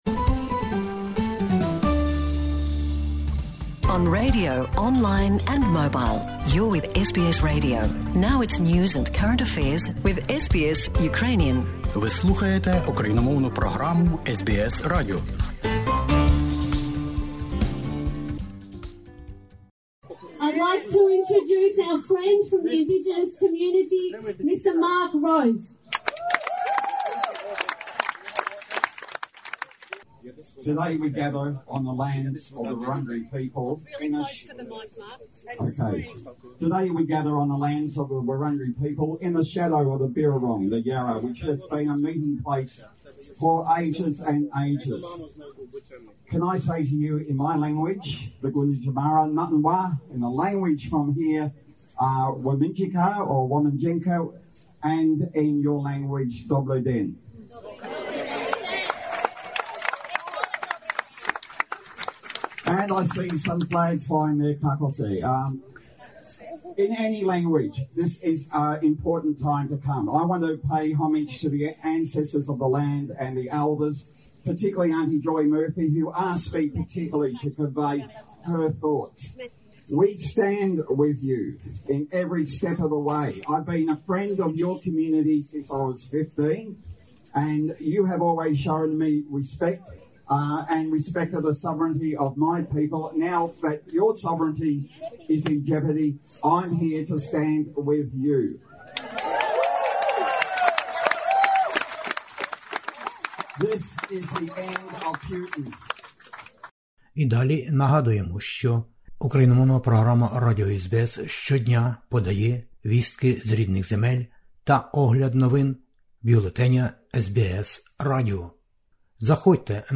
у час маніфестації у Мельбурні...